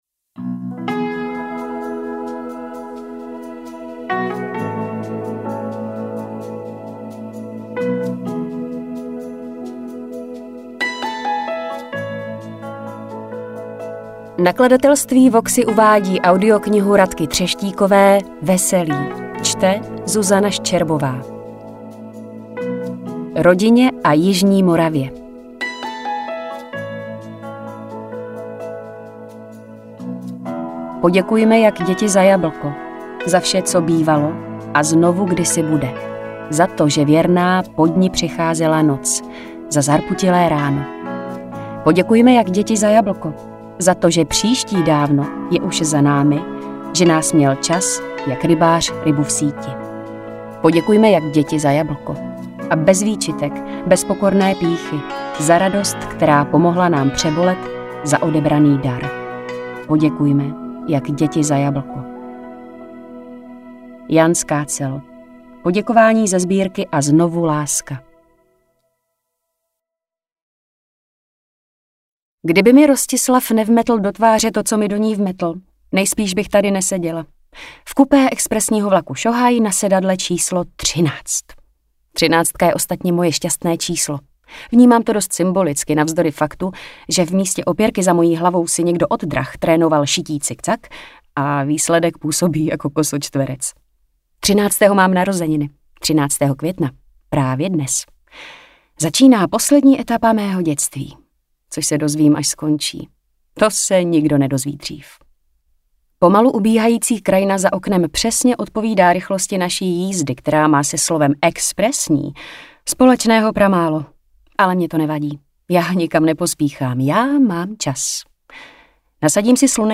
AudioKniha ke stažení, 97 x mp3, délka 10 hod. 47 min., velikost 585,9 MB, česky